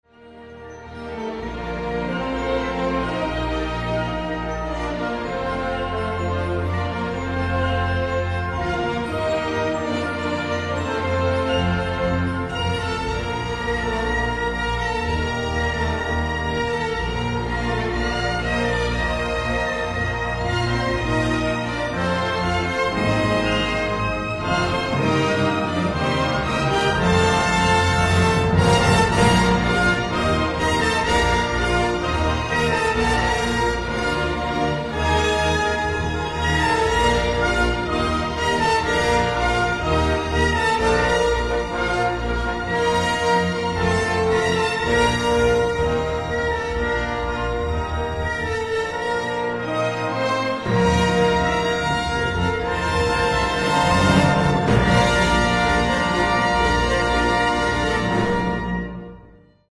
LugarClub Campestre